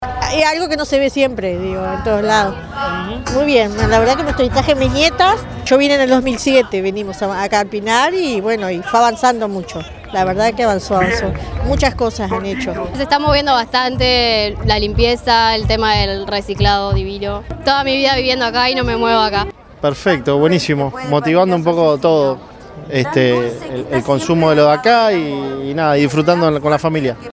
A orillas del arroyo Pando en El Pinar, Ciudad de la Costa celebró sus 29 años con la colocación de un escenario en el que hubo artistas locales, nacionales, una feria de emprendedores y diversas autoridades.
vecinas_y_vecinos.mp3